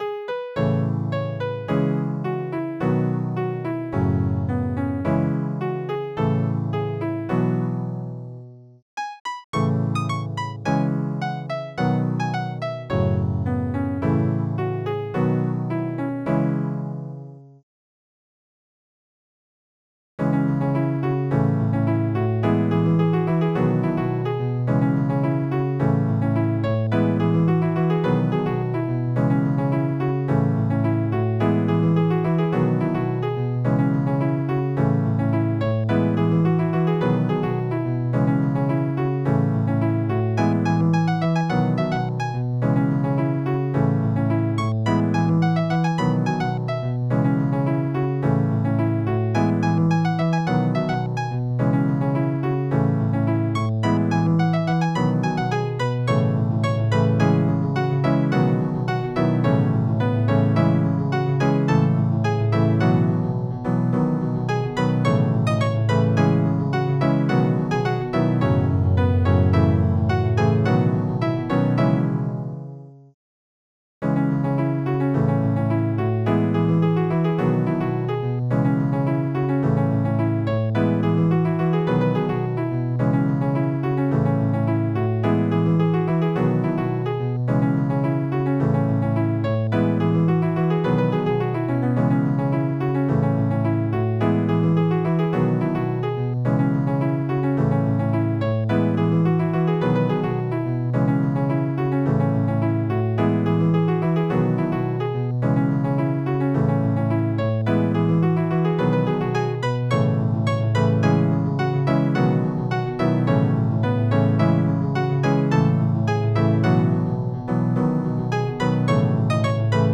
Electronic Piano